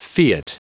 Prononciation du mot fiat en anglais (fichier audio)
Prononciation du mot : fiat